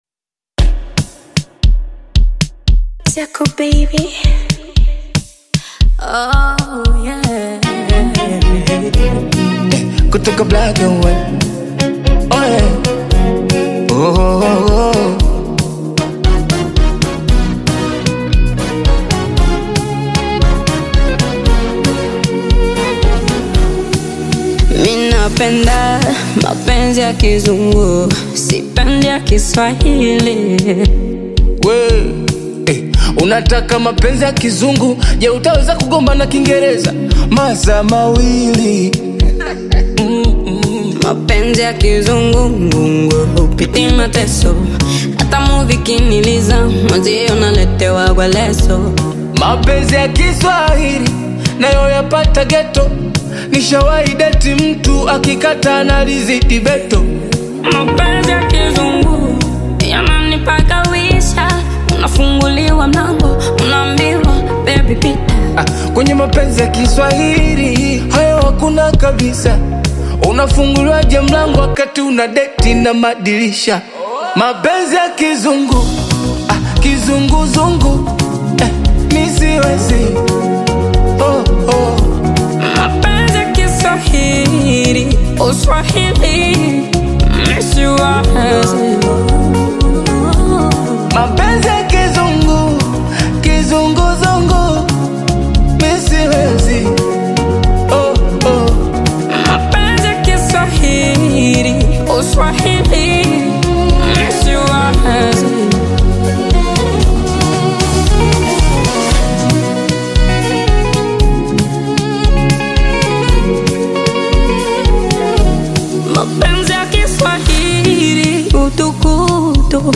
the acclaimed rapper
soulful and mesmerizing hook